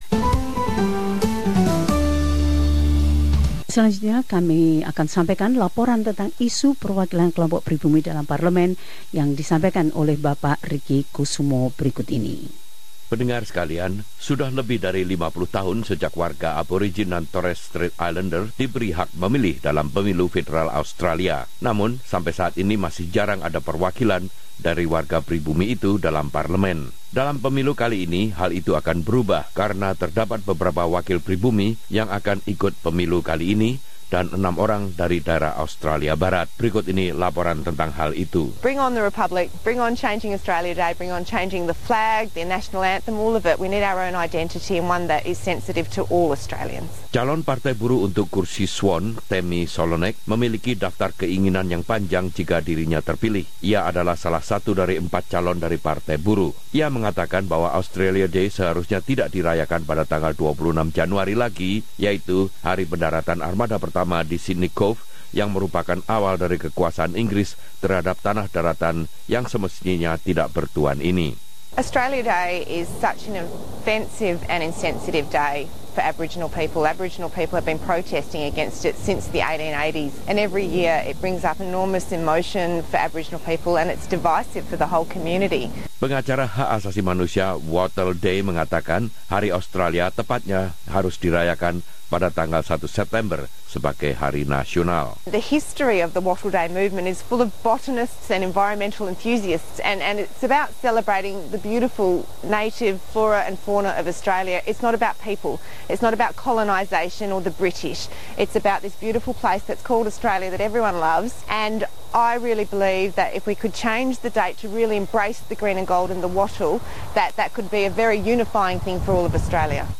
Laporan ini melihat aspirasi dari meningkatnya jumlah kandidat warga Pribumi dalam pemilu 2016.